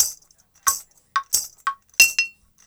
89-PERC3.wav